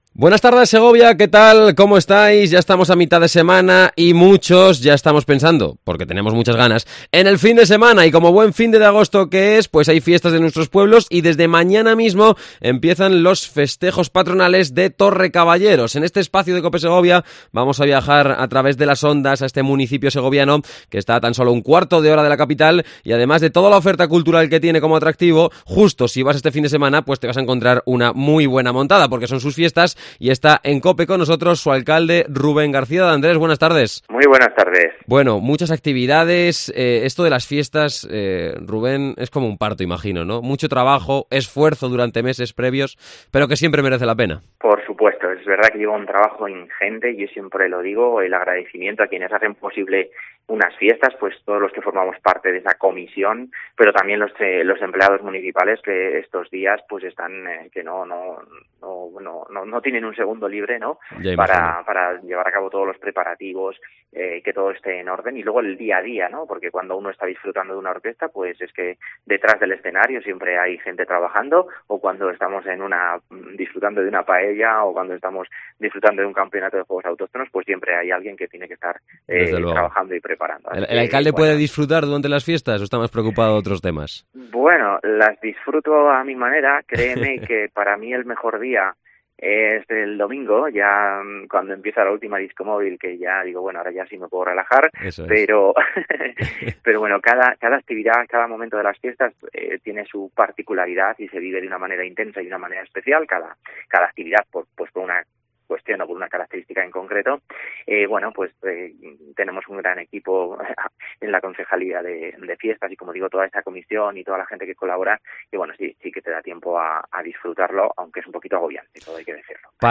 Entrevista a Rubén García, alcalde de Torrecaballeros